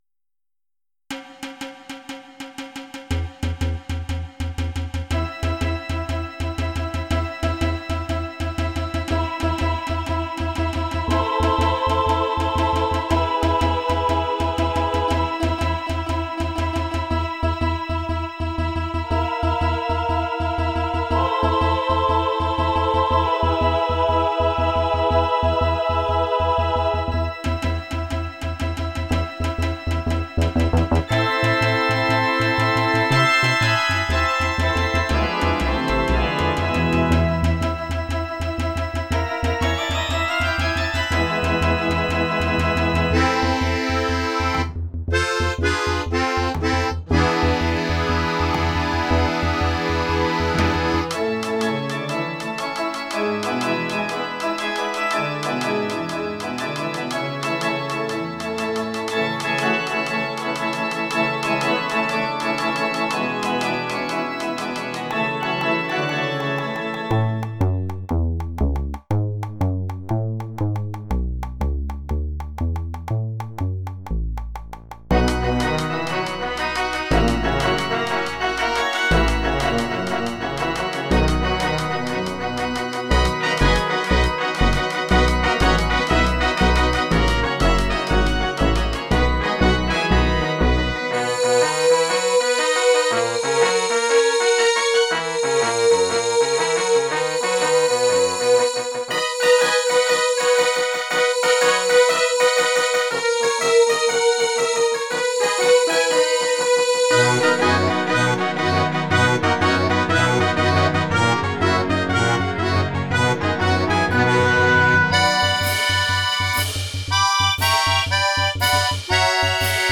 Instrumentierung/Stimmen: Klavier1,Klavier 2, Schlagzeug, Harfe, Streicher, Akkordeon, Hexter Bass 2(30), WhySynth IceChorus, Orgel 1, Orgel 2, Banjo, Trompete1, Trompete2, ah-Chor, Oboe
Das stark rhythmische Geschehen ist dann, allein vom Schlagzeug und Zupfbass getragen, als Wiederholung auch salonfähig. Es folgen Wiederholungen mit erhöhter Tonart und variierter Instrumentierung.